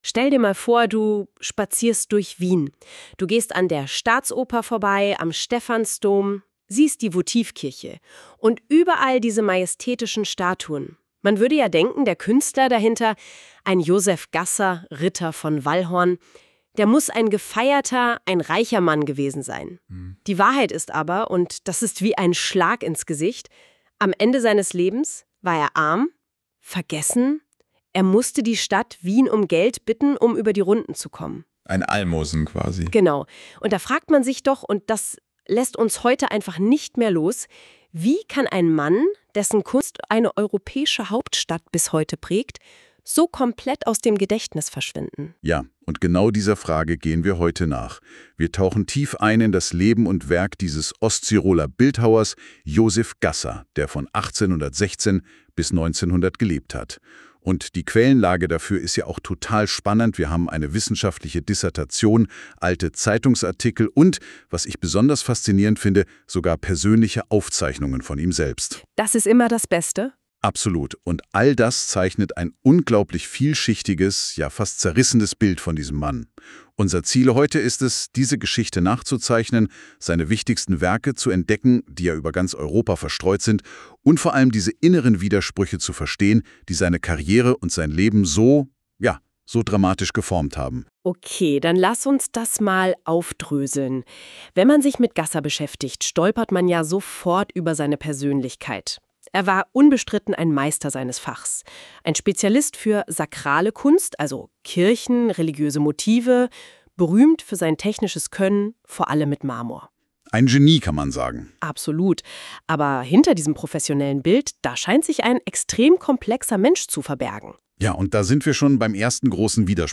Audio-Zusammenfassung KI Generiert Erleben Sie die wichtigsten Stationen des "Ritters von Valhorn" als spannendes Gespräch.
Erleben Sie ein lebendiges Gespräch über das Lebenswerk von Josef Gasser.
↓ Präsentation laden Format: PDF Ein kleiner Begleithinweis: Diese Inhalte wurden mithilfe moderner KI-Technologie erstellt.